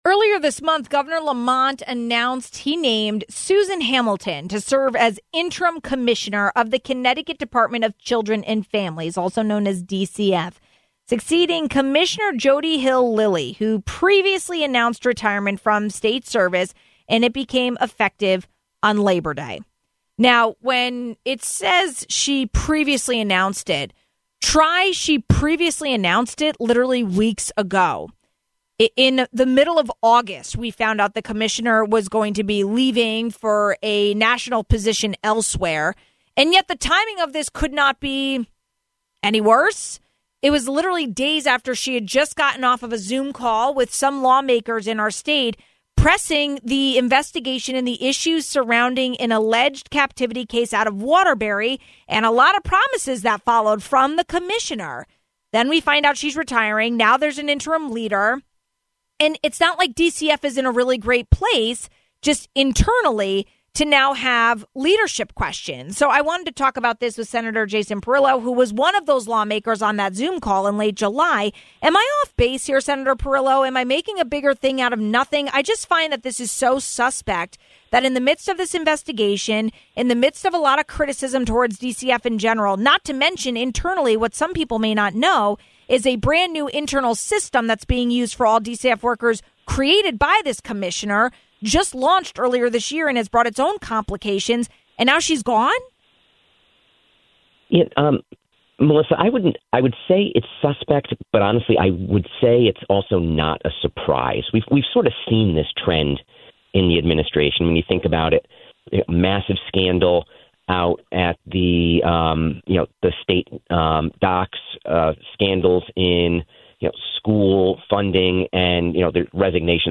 Amid an investigation of an alleged captivity case in Waterbury and many other criticisms towards the Department of Children and Families, the commissioner retired. A new interim commissioner has been appointed but there are still a lot of questions following the abrupt exit of the former commissioner. We talked more about it with Senator Jason Perillo, ranking senator for the Committee on Children.